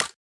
LeverA.wav